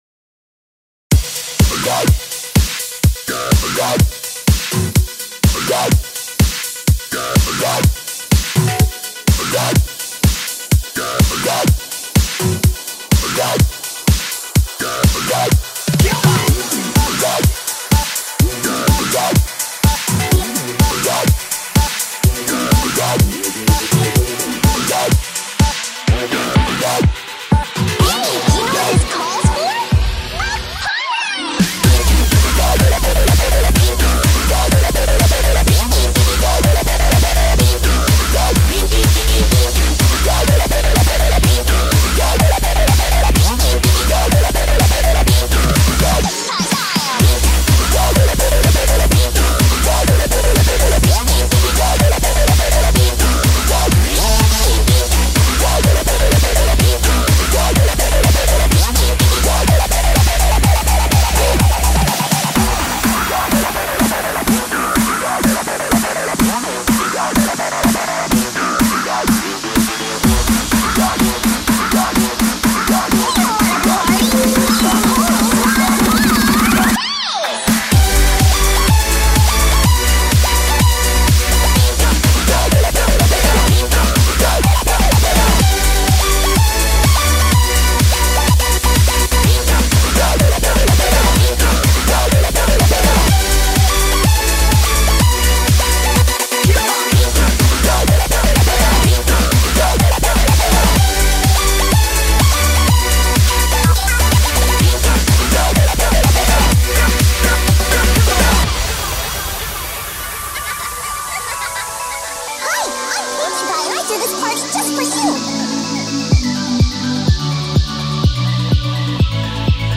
genre:dubstep